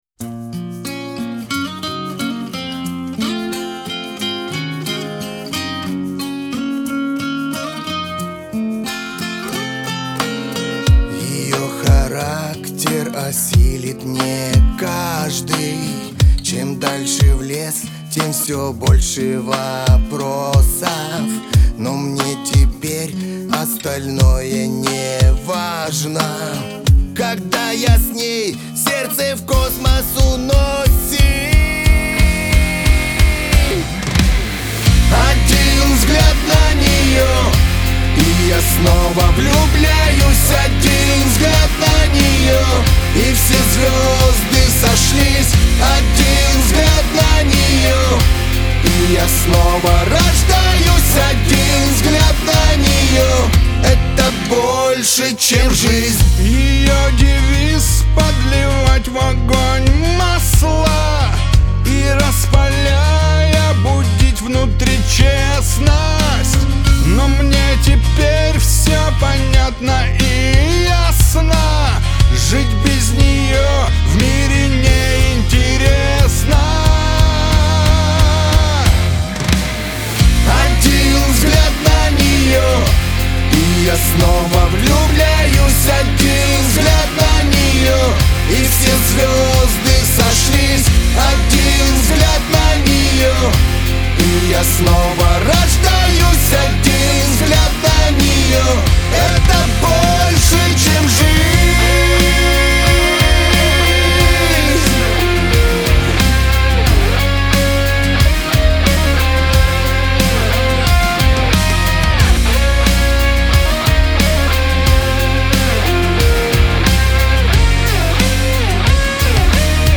Категории: Русские песни, Рок.